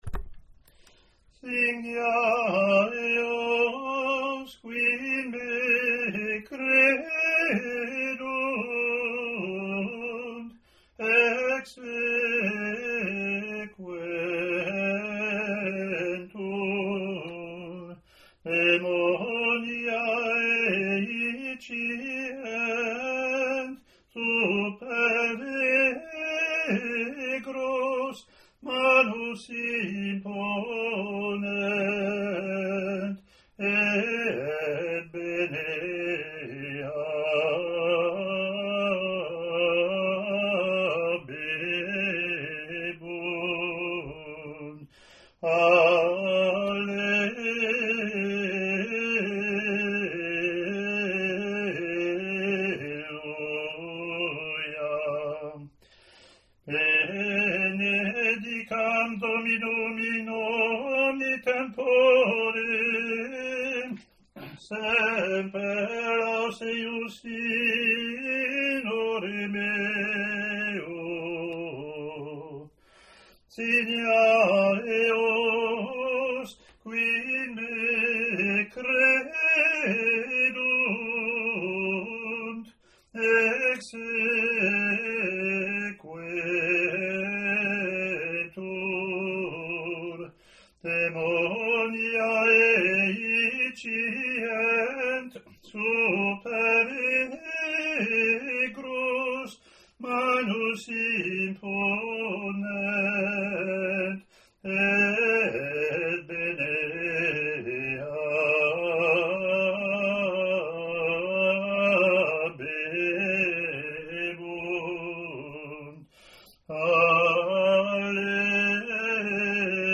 Year B Latin antiphon + verse; Year C Latin antiphon + verse)
eaal-b-communion-gm.mp3